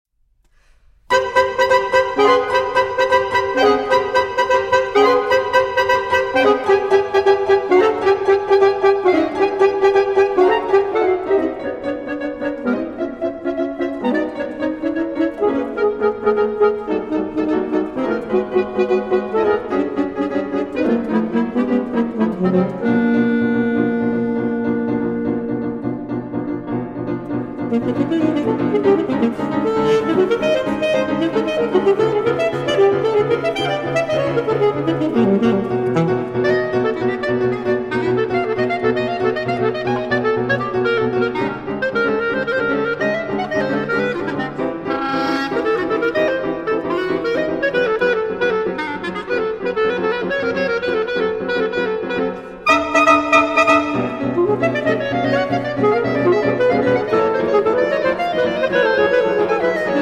Saxophone
Clarinet
Piano